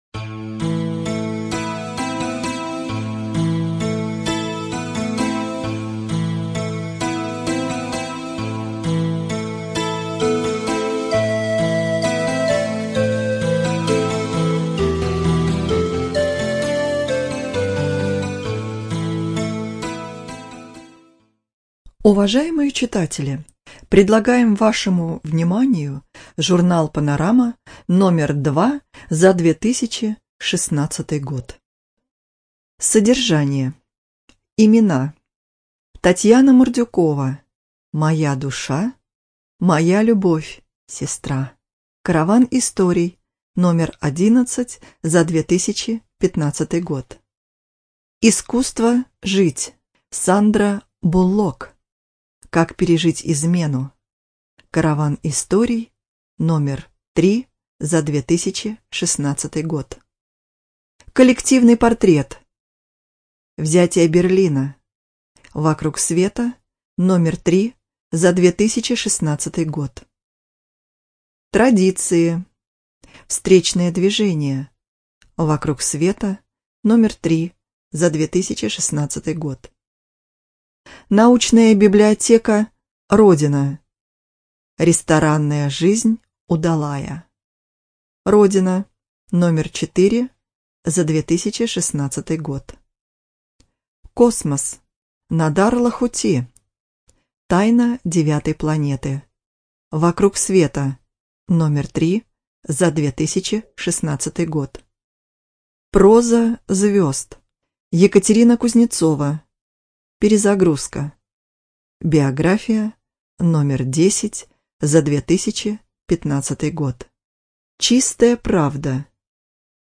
Студия звукозаписиТверская библиотека для слепых имени Михаила Ивановича Суворова